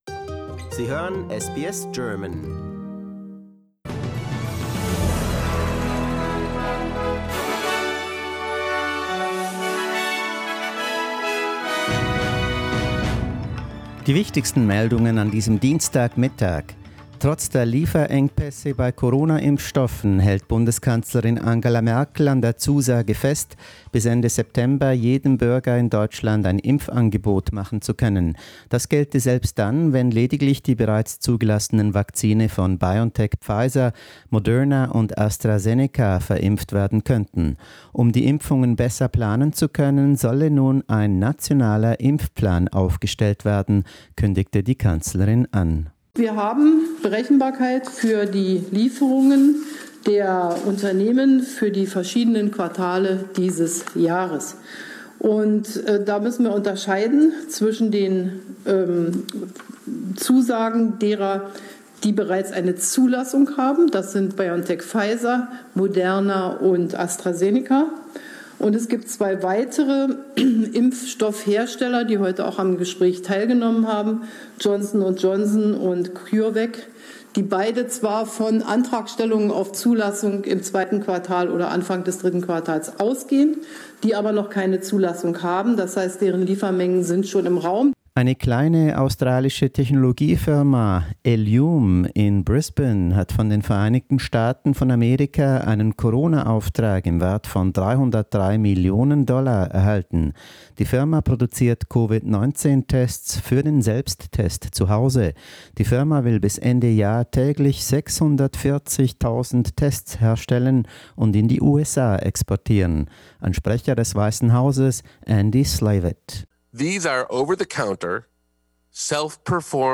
SBS News-Flash am Mittag, Dienstag 02.02.21